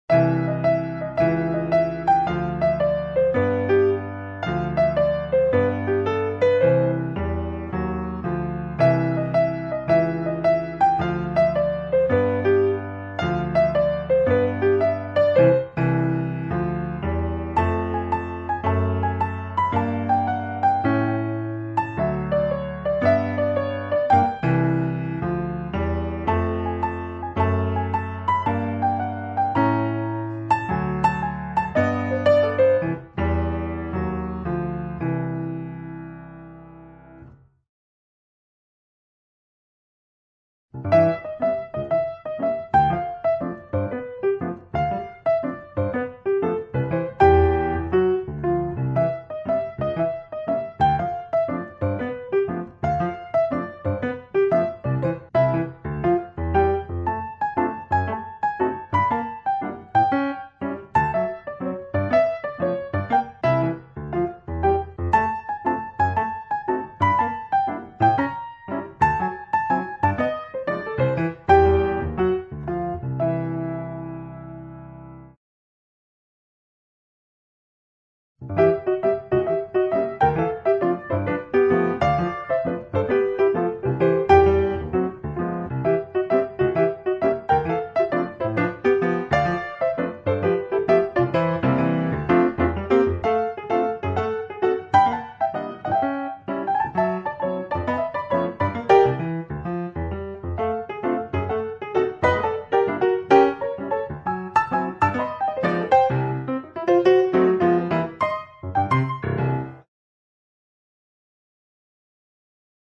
Arranjo.